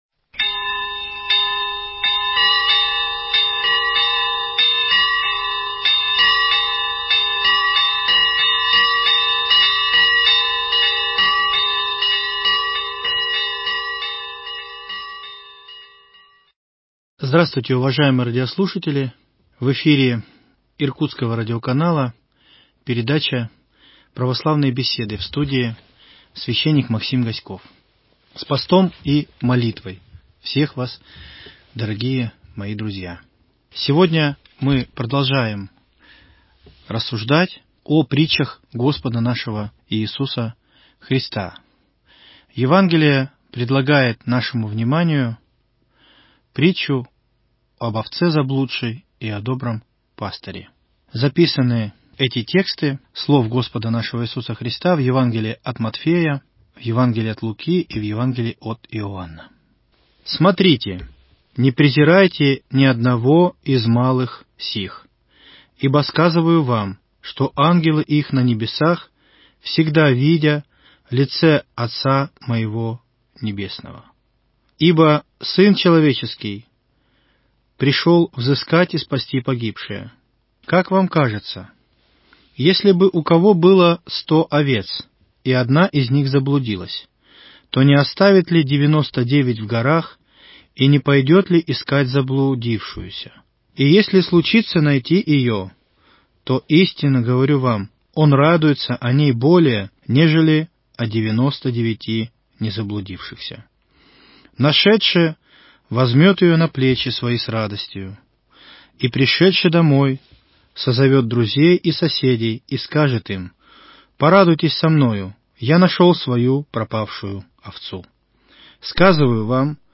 Передача «Православные беседы».